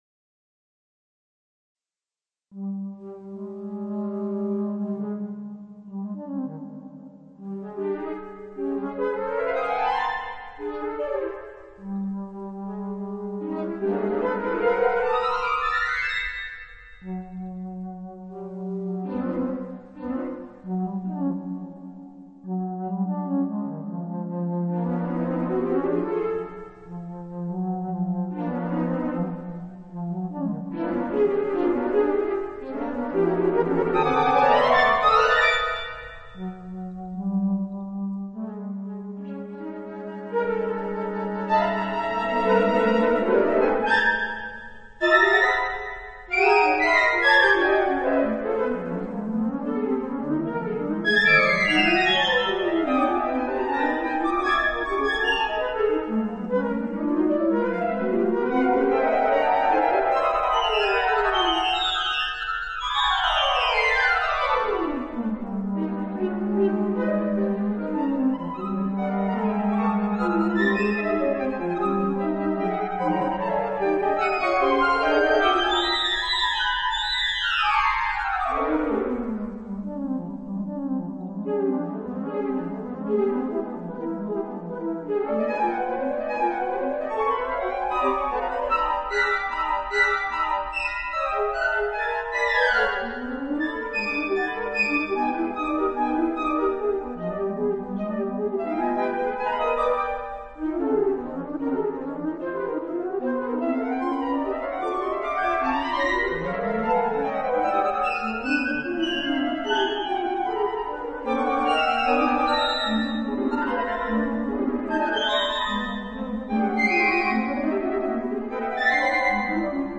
L’autre pièce pour flûte,
C’est original, en tout cas pour moi qui ne suis pas familier de ce répertoire; c’est « quick » en effet, et bien dans ton style, bruissant, frémissant, bondissant, très beau concert de flutes, aux confins de l’atonal comme je l’aime…quand je pense à  tes déclarations d’antan sur la musique atonale, tu te souviens?